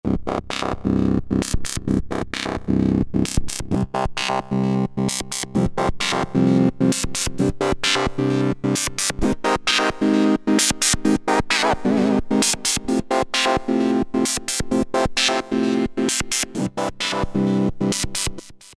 F10 - Trip 2 Ibiza The darker side of trance